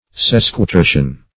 Search Result for " sesquitertian" : The Collaborative International Dictionary of English v.0.48: Sesquitertian \Ses`qui*ter"tian\, Sesquitertianal \Ses`qui*ter"tian*al\, a. [Sesqui- + L. tertianus belonging to the third.